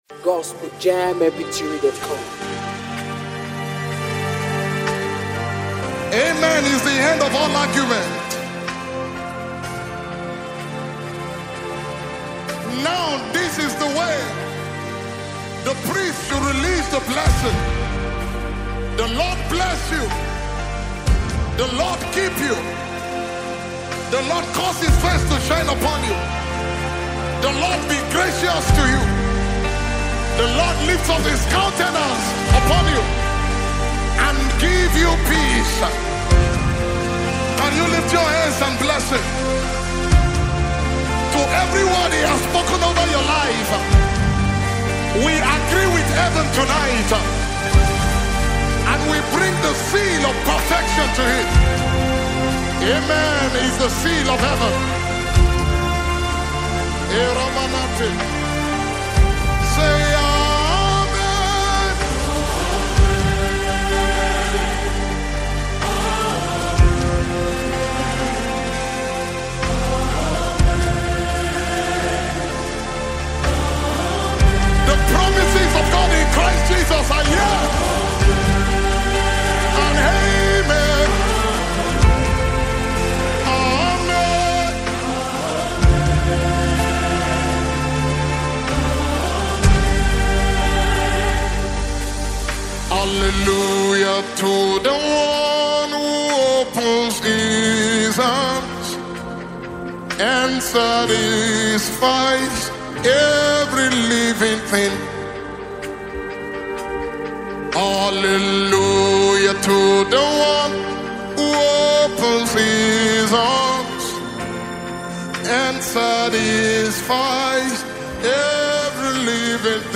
prophetic worship song
Atmospheric worship